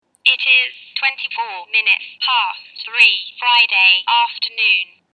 Slijepe osobe – Govorni ručni satovi DianaTalks govore 10 jezika, koriste prirodni ljudski glas.